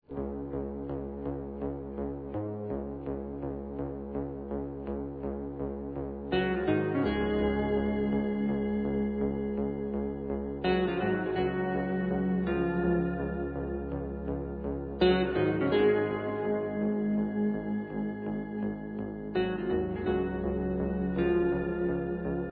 Recorded at Nemo Studios, London, England, 1977